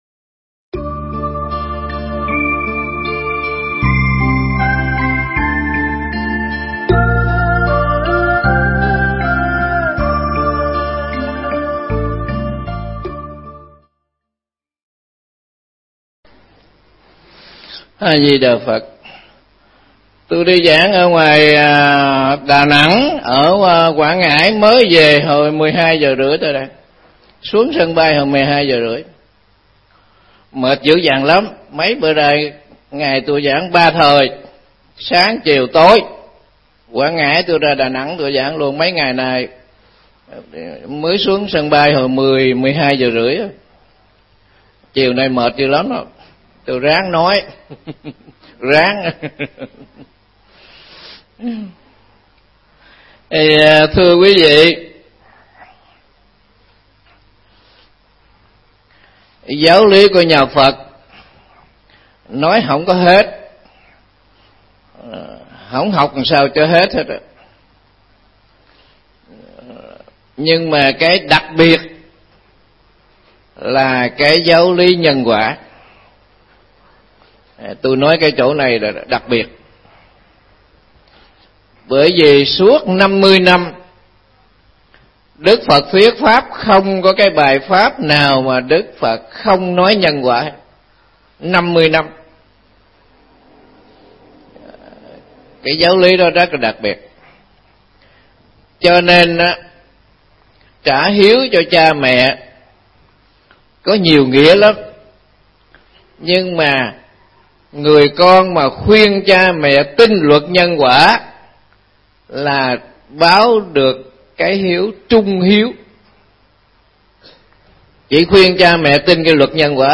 Nghe Mp3 thuyết pháp Nhân Quả